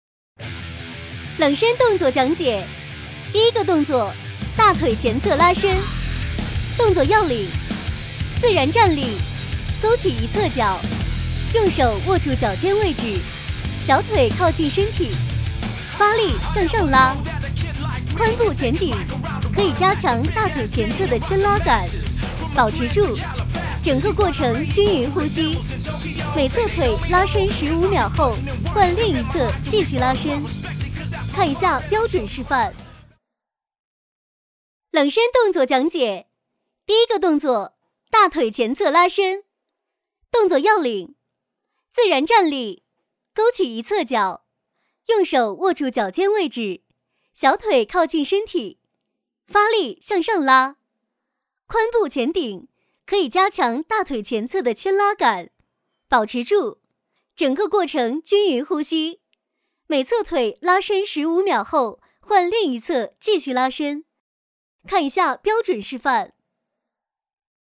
女36-【健身】KEEP燃脂训练
女36年轻多风格 v36
女36--健身-KEEP燃脂训练.mp3